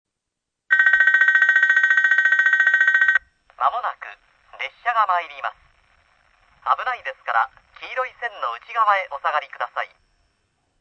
◆　〜旧放送　（2002年取材分）
上下線で「九州汎用型放送B」が流れます。
＜スピーカー＞　TOAホーン中
＜曲名（本サイト概要）＞　九州汎用型B　／　上り線-男性放送　下り線-女性放送
↓接近放送・・・・・おおよそ60秒前。
△接近放送・男性